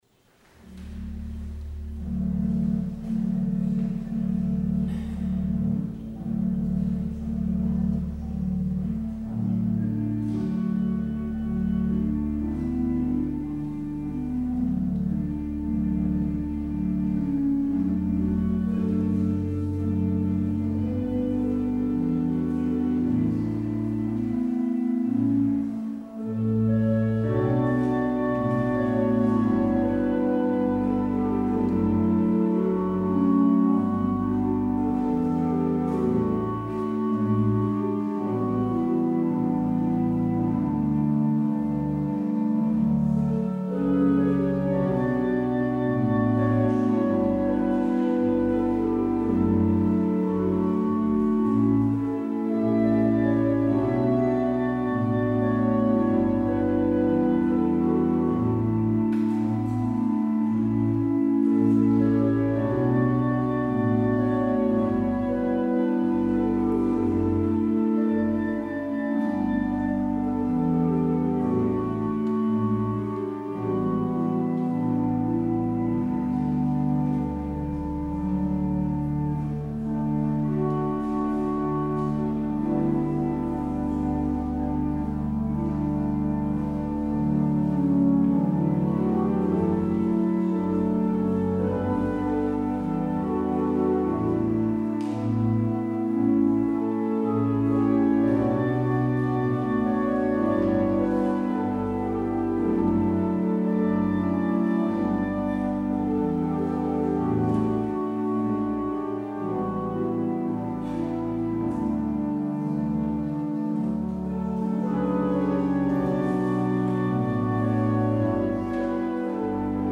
 Luister deze kerkdienst terug: Alle-Dag-Kerk 13 september 2022 Alle-Dag-Kerk https